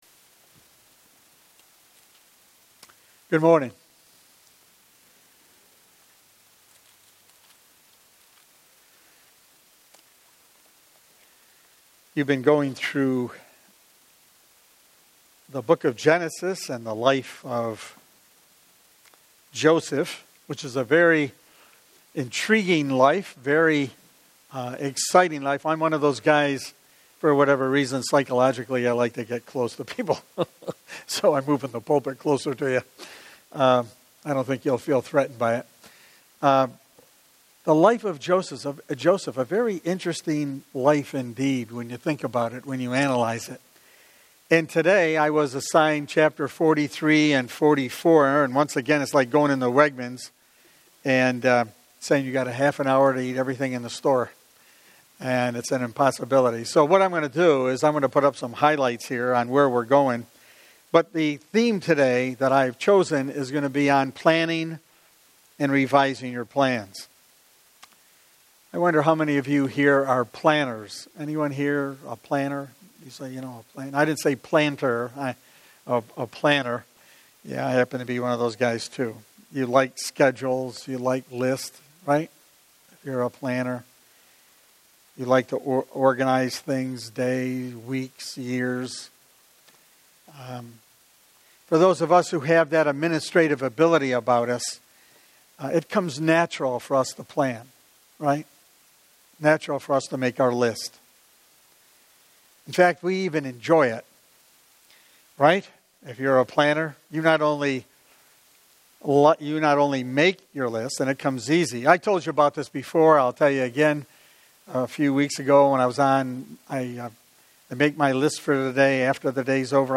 Bible Text: Genesis 43-44 | Preacher